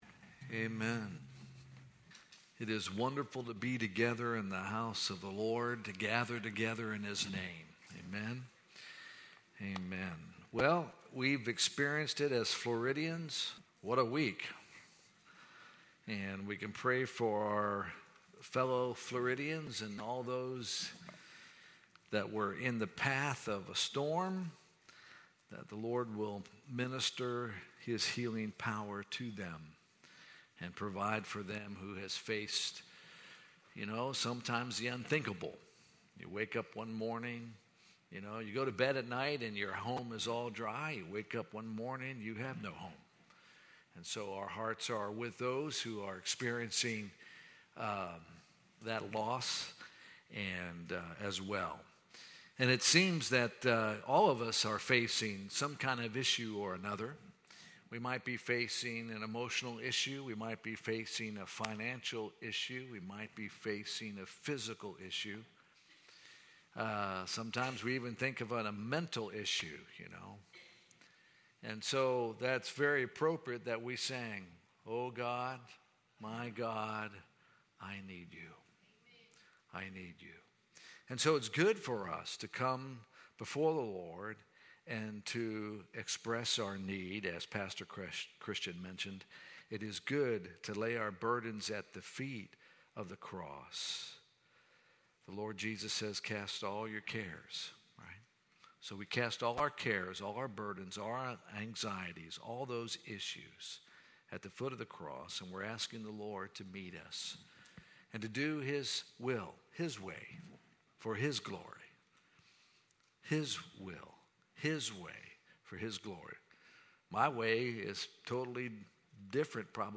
Sermons | Alliance church of Zephyrhills